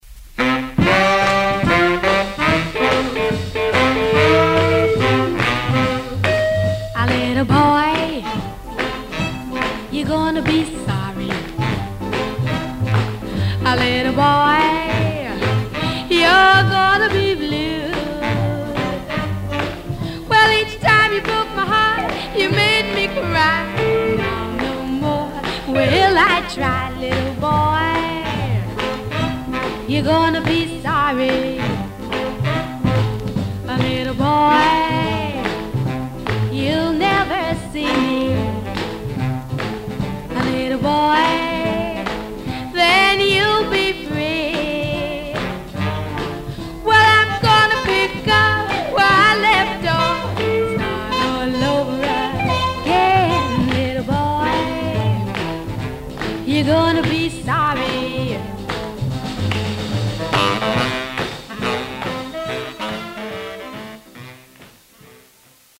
女性R&Bシンガー。
ブラス入りのミディアム・アップとスロウ・バラード。
［ モノラル ］
ポイント： 45 Pt --- VG+〜VG++ サーフィス・ノイズが少なめでヌケの良い音です。